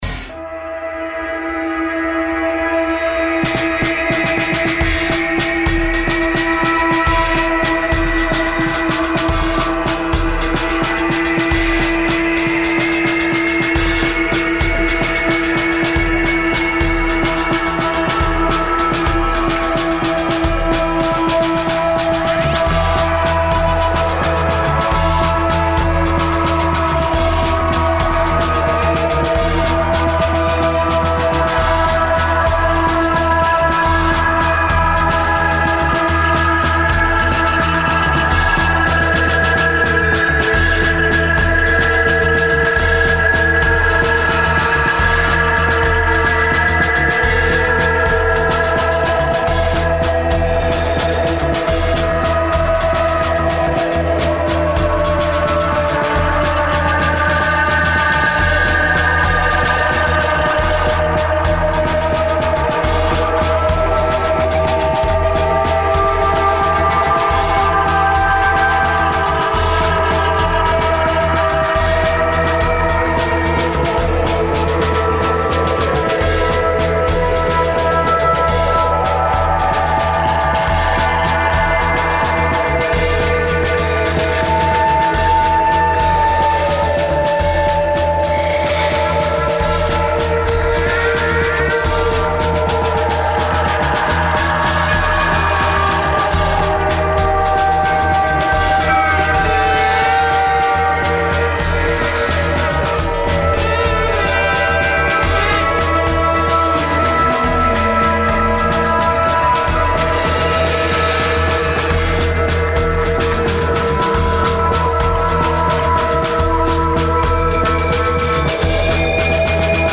Un’ora di divagazione musicale